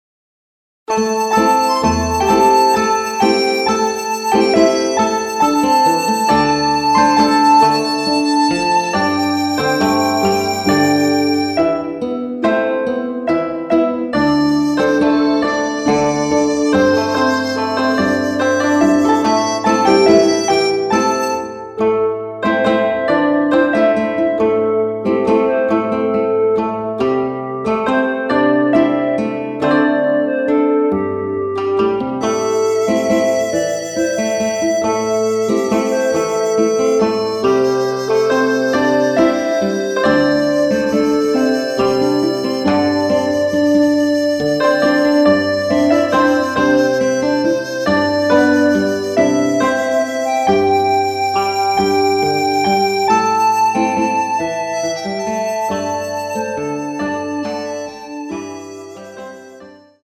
국악기 음원으로 제작 하였습니다.
원키에서 (-1)내린 멜로디 포함된 MR 입니다.(미리듣기 참조)
Am
앞부분30초, 뒷부분30초씩 편집해서 올려 드리고 있습니다.